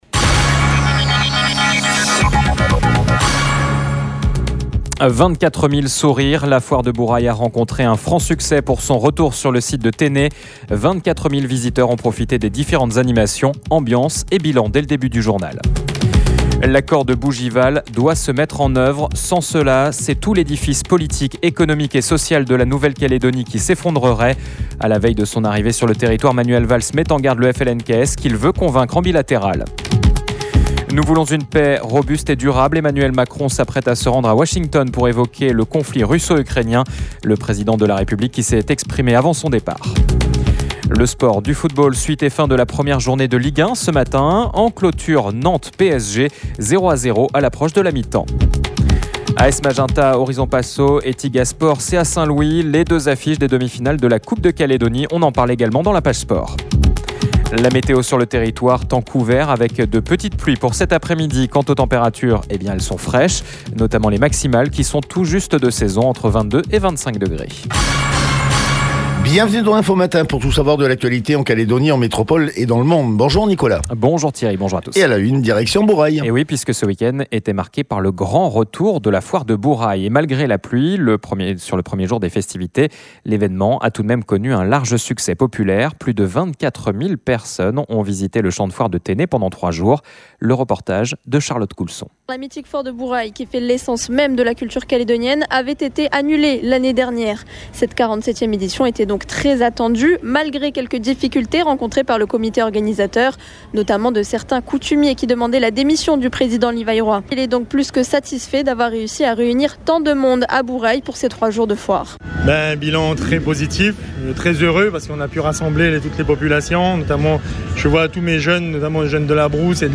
Le Journal